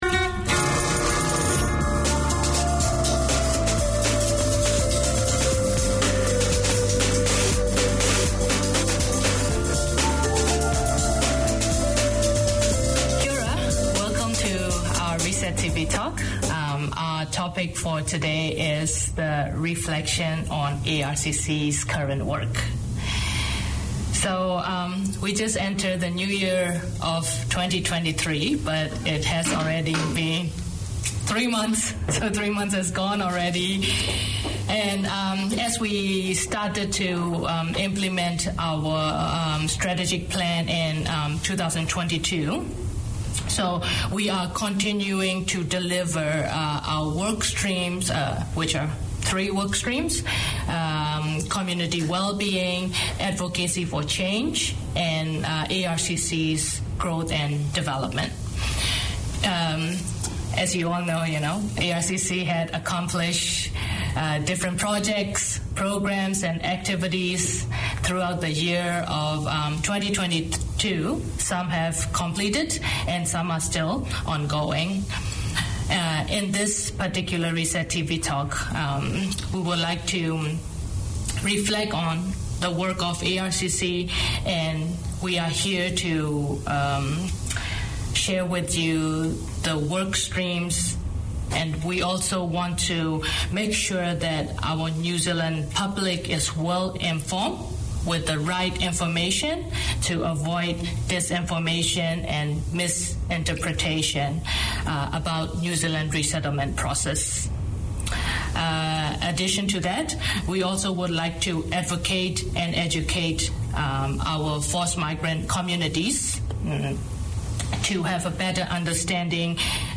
The radio show aims to open discussion on the progress of former refugees in Aotearoa/NZ through interviews, debate, news and talkback. Each week Resett Radio welcomes guests, listens to their stories and music and explores current affairs as they relate to resettlement communities today.